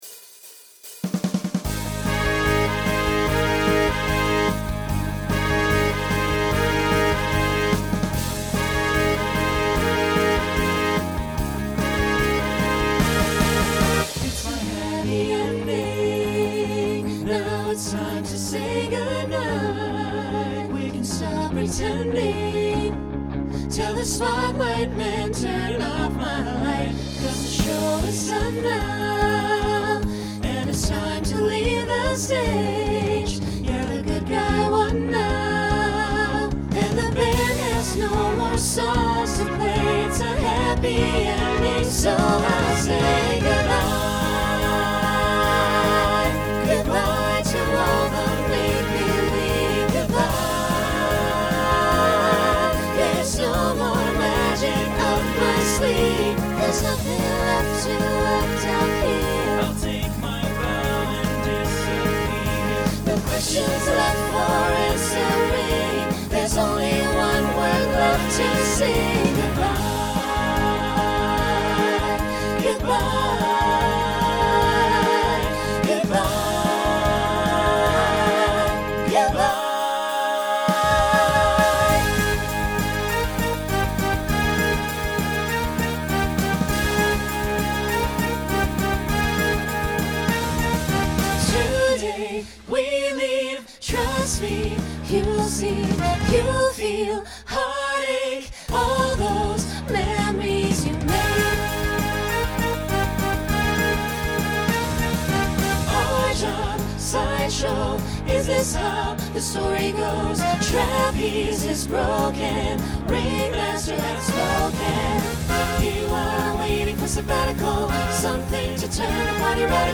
Broadway/Film , Rock Instrumental combo
Voicing SATB